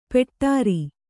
♪ peṭṭāri